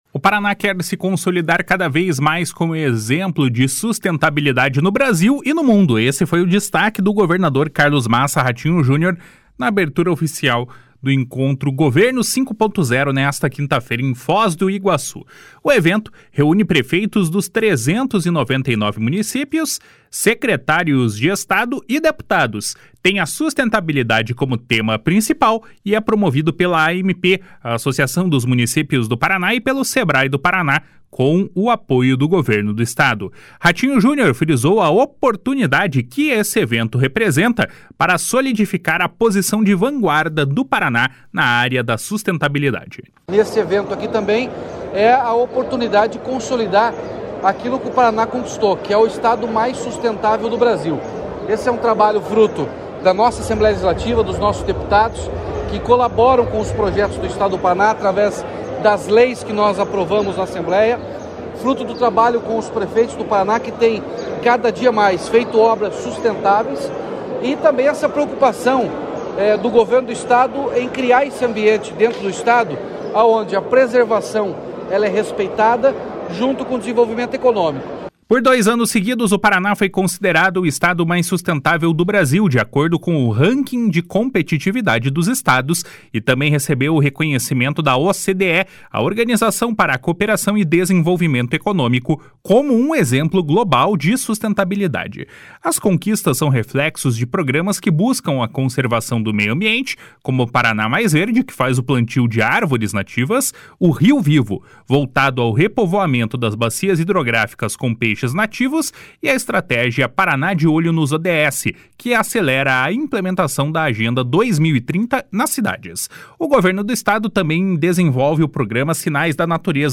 // SONORA RATINHO JUNIOR //
// SONORA JUNIOR WEILLER //